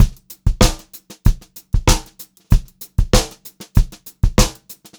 96POPBEAT2-L.wav